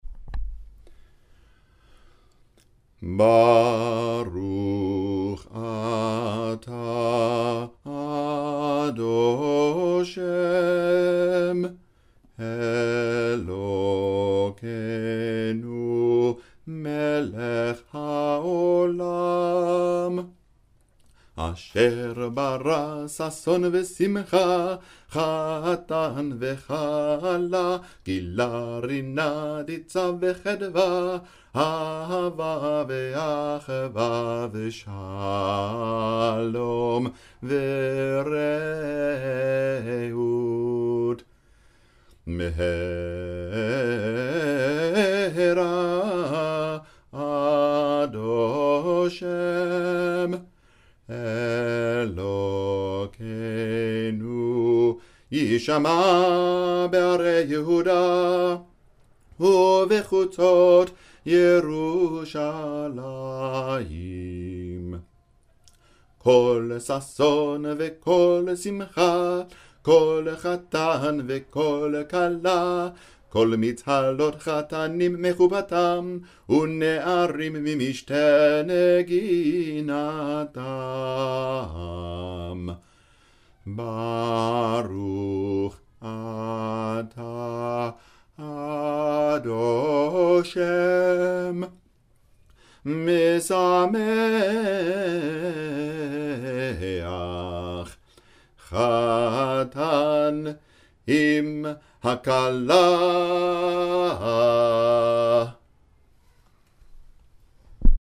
a (quickly-recorded) MP3 of me singing it to my website.